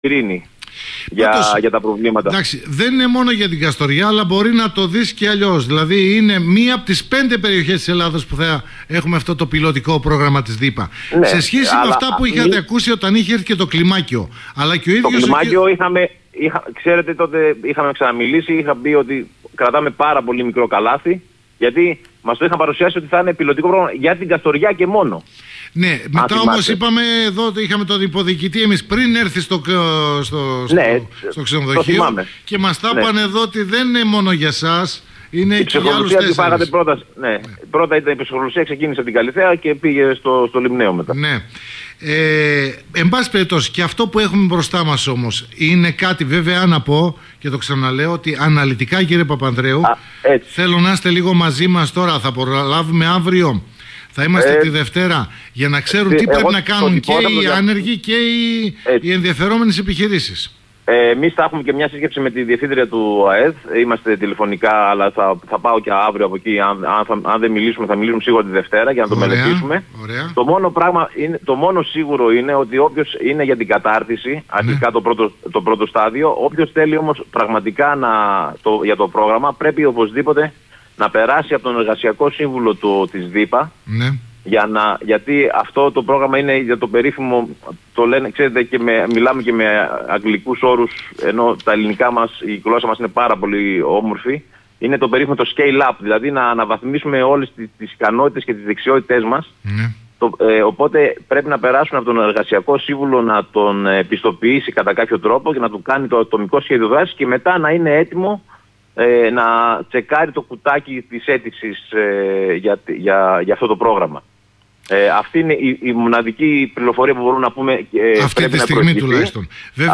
(συνέντευξη)